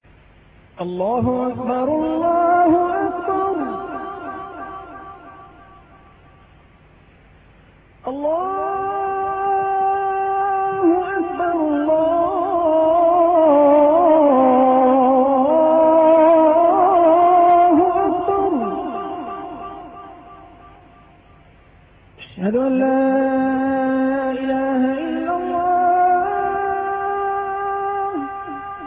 azan1-1-audi.mp3